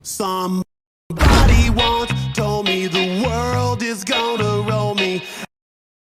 Короткая мелодия